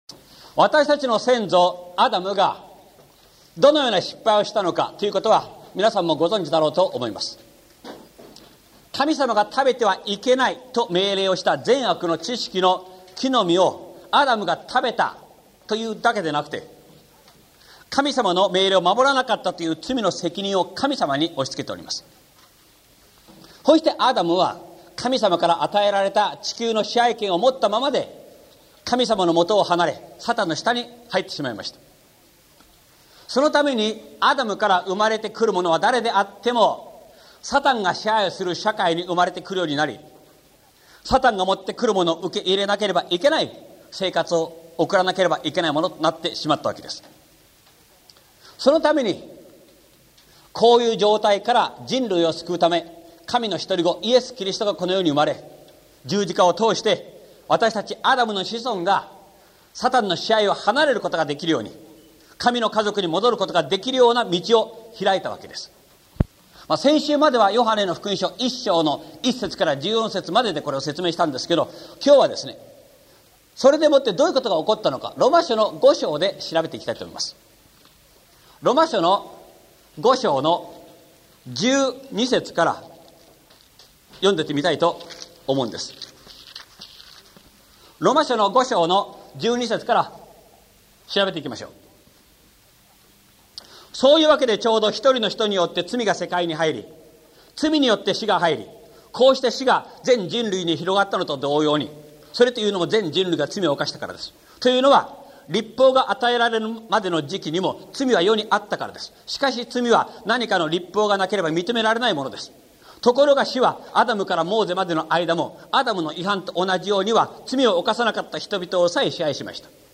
礼拝・聖書講座の録音データダウンロード | 国際クリスチャンセンター | 東京の教会